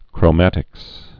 (krō-mătĭks)